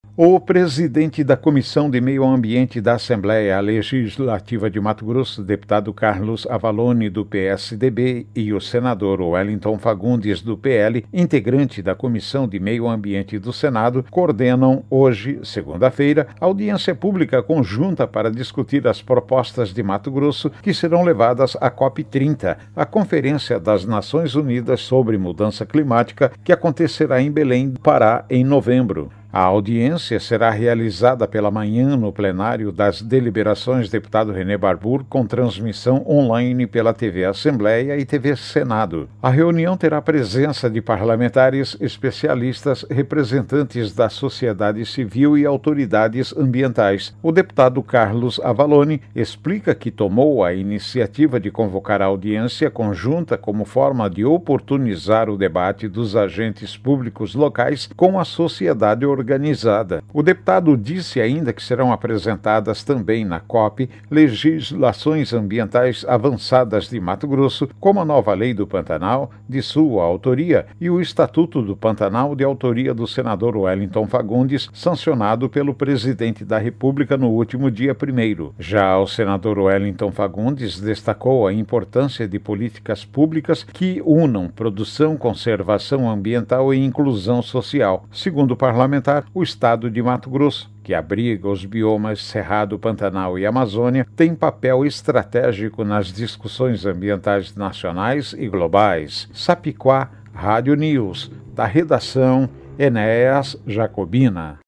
Boletins de MT 20 out, 2025